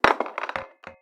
DCA Wood Block Break and Fall
android cartoon droid effect robot sci-fi sfx sound-design sound effect free sound royalty free Sound Effects